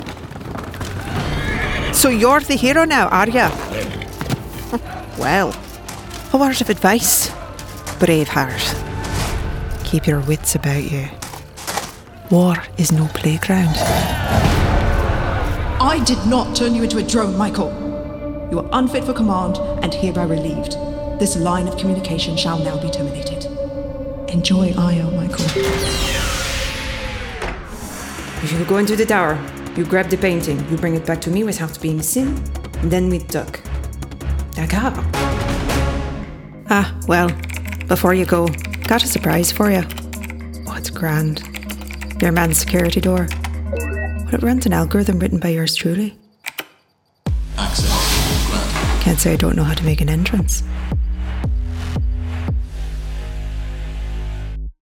Englisch (Kanadisch)
Hauptmikrofon: Aston Spirit
Studio: Maßgeschallte, permanente Heimkabine (-79dB Geräuschpegel)
Im mittleren Alter